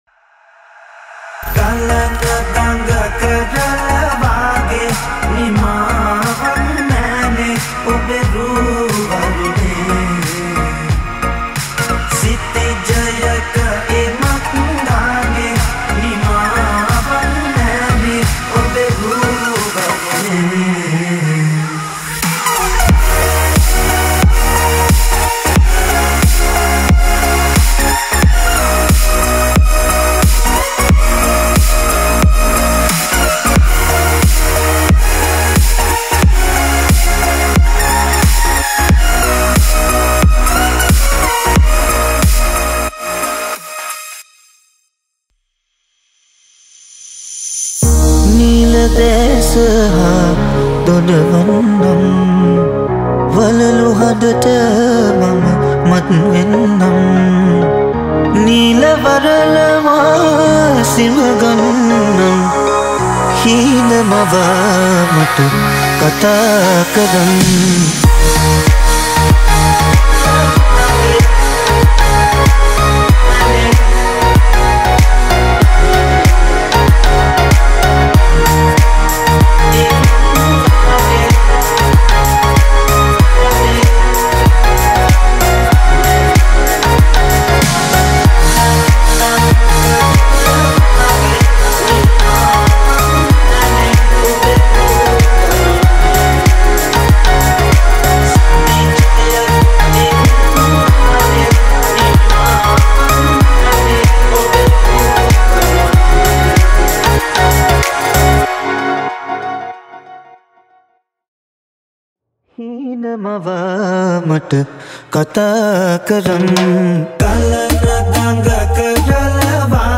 High quality Sri Lankan remix MP3 (8).